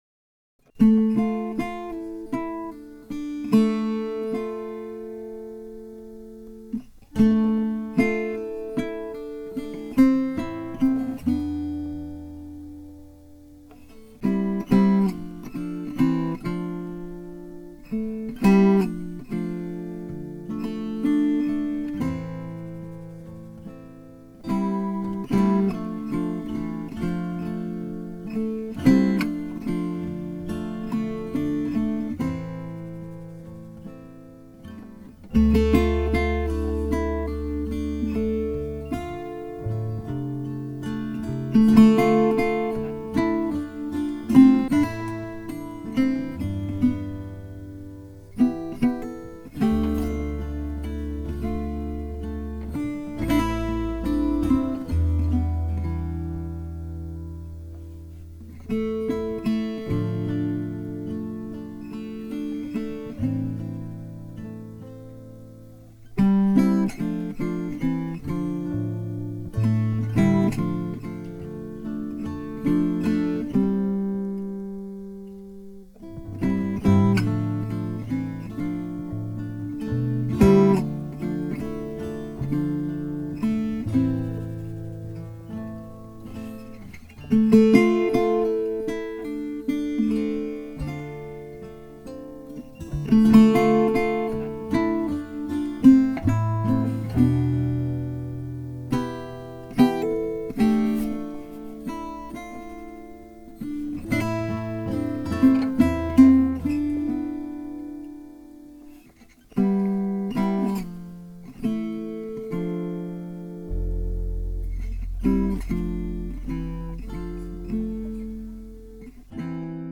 The lead instrument in this tune is not a guitar, but a Victor banjola.